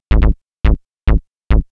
Techno / Bass / SNTHBASS124_TEKNO_140_A_SC2.wav
1 channel